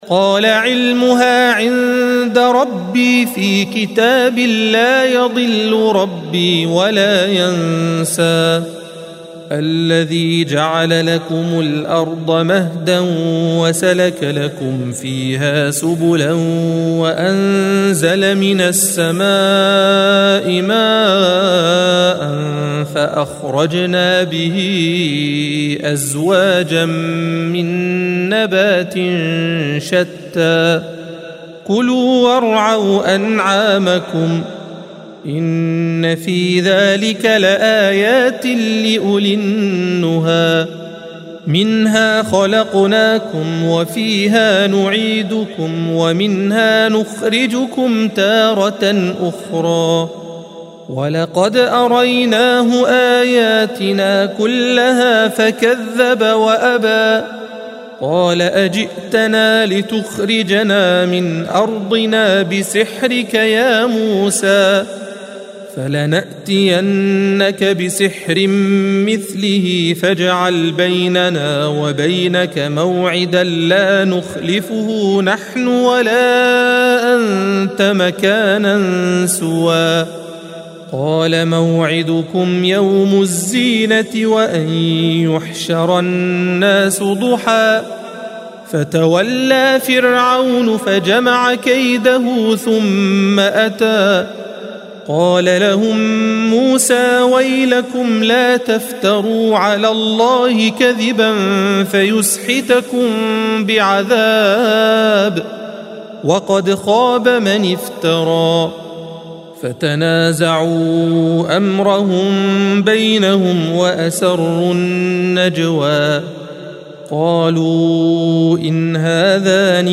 الصفحة 315 - القارئ